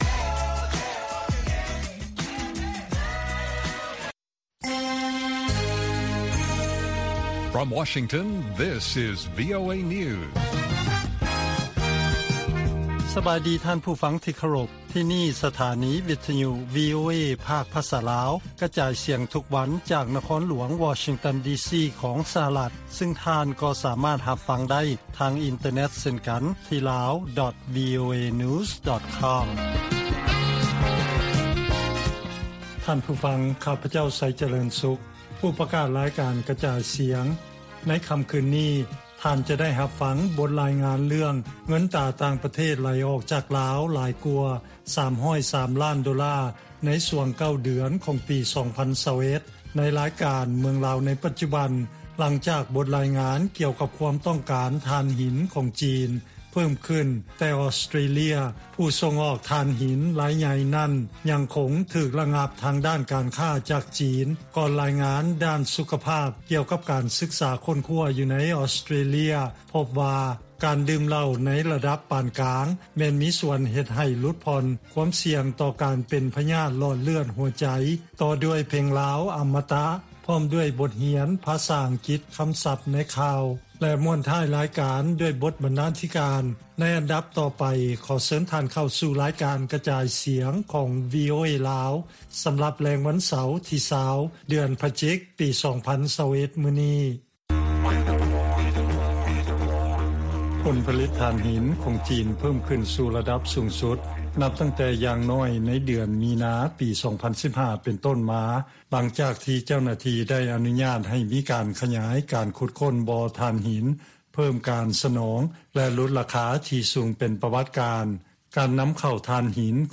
ວີໂອເອພາກພາສາລາວ ກະຈາຍສຽງທຸກໆວັນ ຈາກວໍຊິງຕັນ ເລື້ອງສຳຄັນໃນມື້ນີ້ມີ: 1.